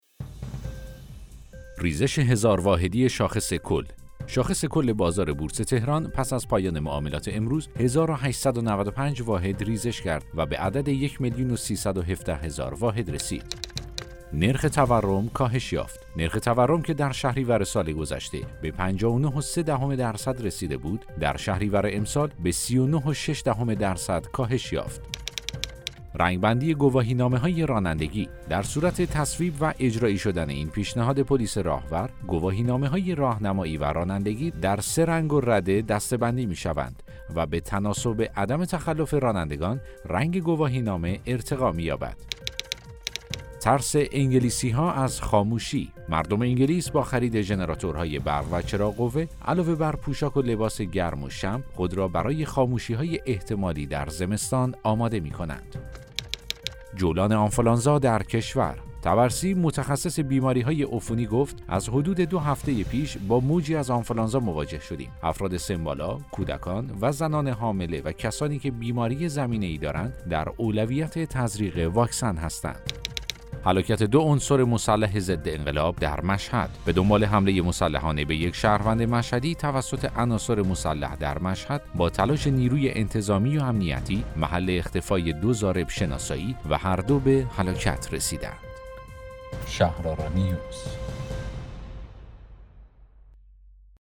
اخبار صوتی - شنبه ۲۳ مهر ۱۴۰۱